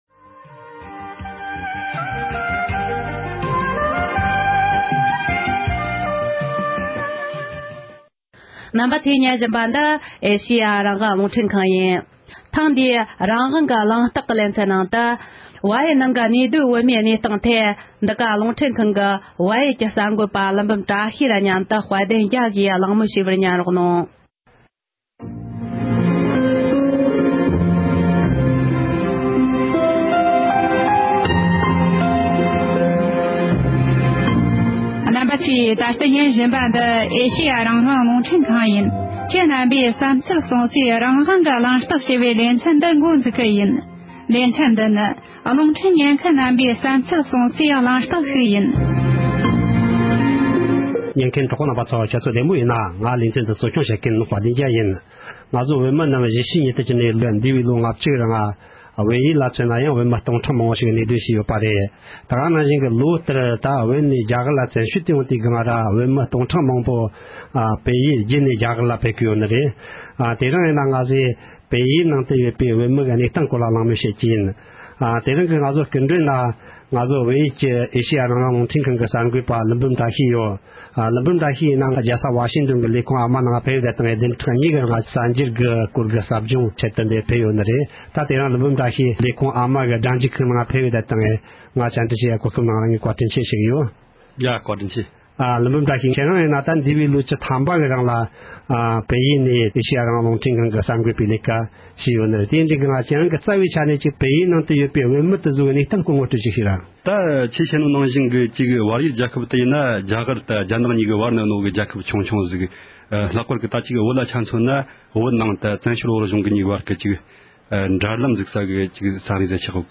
བལ་ཡུལ་དུ་གནས་སྡོད་བོད་མི་ཚོའི་གནས་སྟངས་ཐད་གླེང་མོལ།